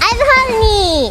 Worms speechbanks
Dragonpunch.wav